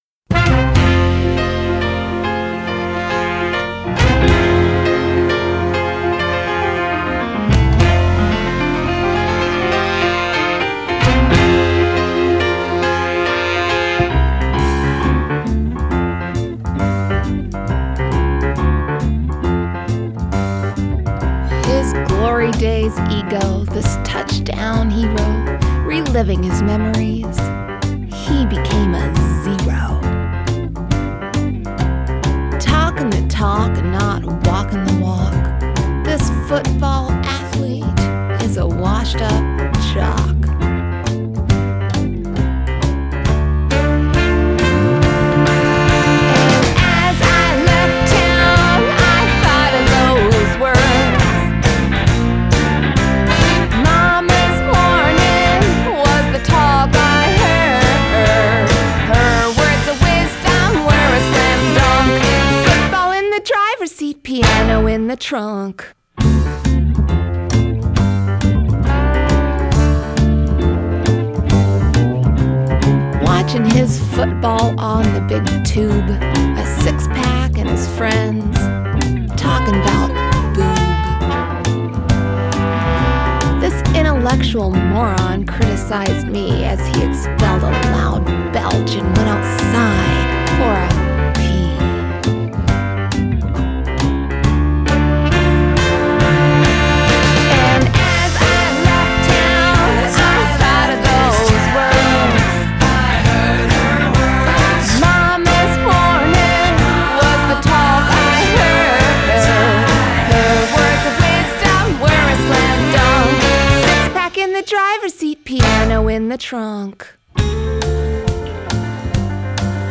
Adult Contemporary , Comedy
Indie Pop , Musical Theatre